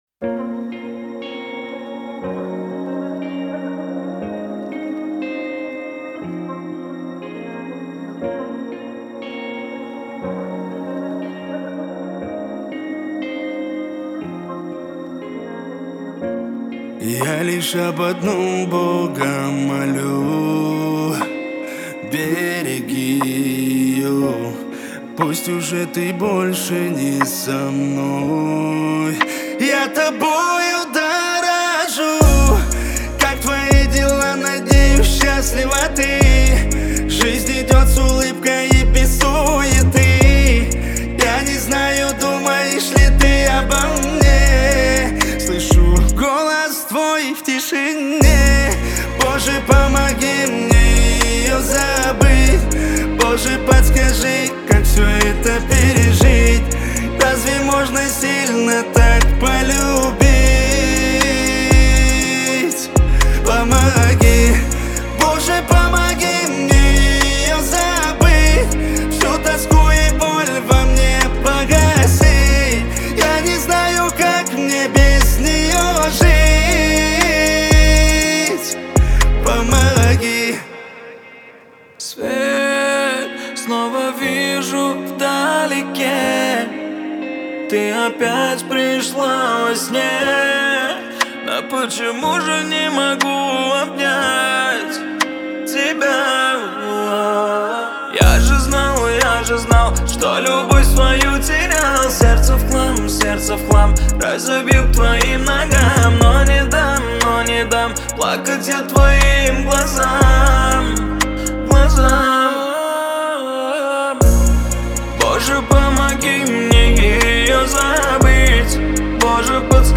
Качество: 320 kbps, stereo
Кавказская музыка